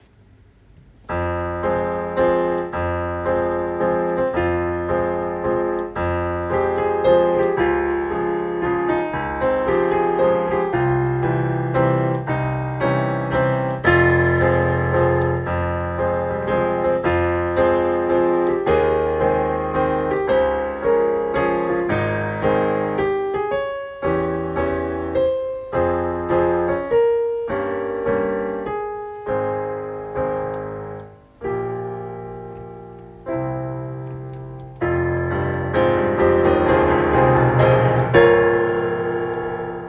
It’s so smooth.